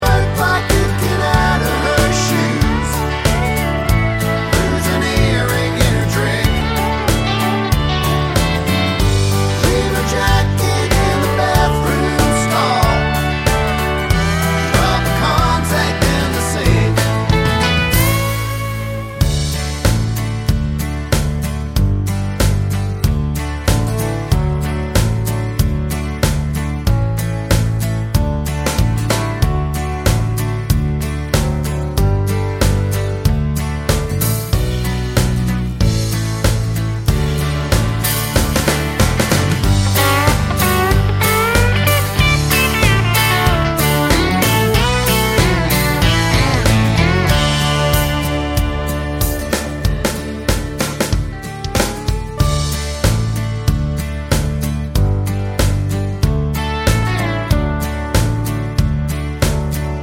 no Backing Vocals Country (Male) 3:00 Buy £1.50